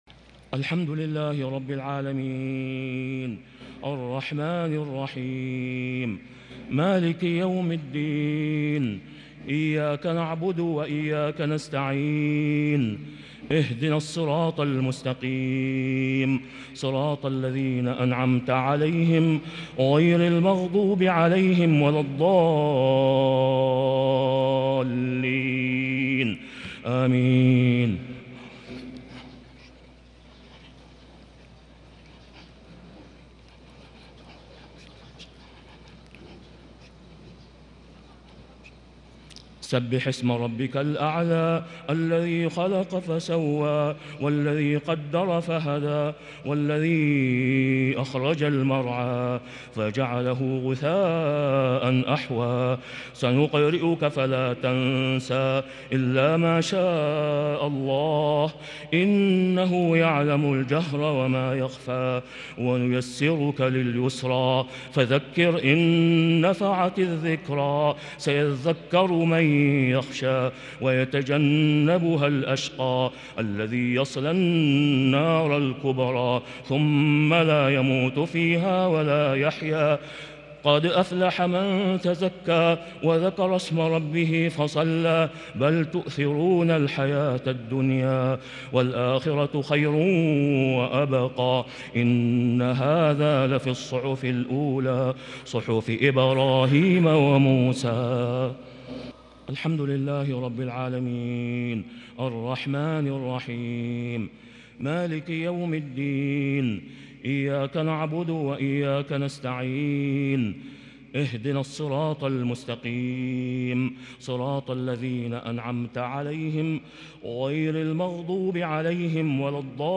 صلاة الجمعة | ٢٧ شعبان ١٤٤٢هـ | سورتي الأعلى والغاشية | Jumu’ah prayer from Surah Al-Ala & Al-Ghashiyah 9-4-2021 > 1442 🕋 > الفروض - تلاوات الحرمين